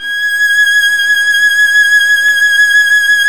Index of /90_sSampleCDs/Roland - String Master Series/STR_Violin 2&3vb/STR_Vln2 mf vb